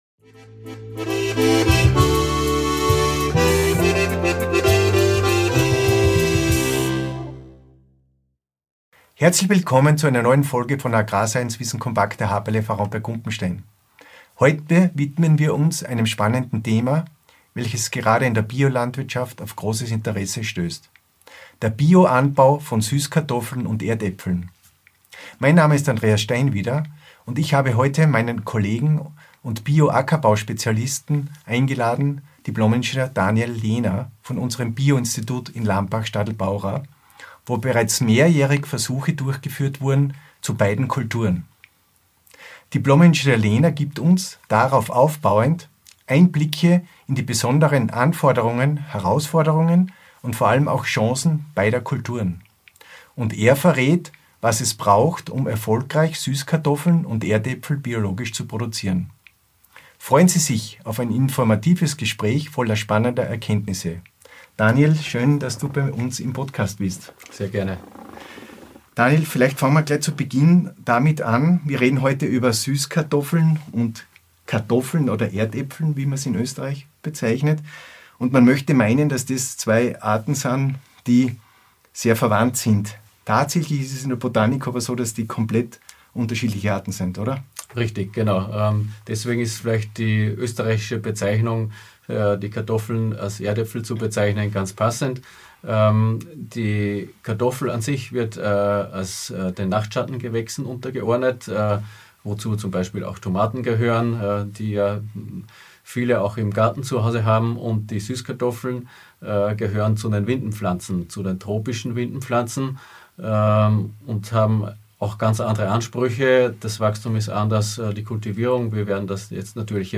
Warum Süßkartoffeln gerade jetzt in Österreich gefragt sind und was es braucht, um beide Kulturen nachhaltig zu kultivieren – das erfahren Sie in diesem spannenden Gespräch!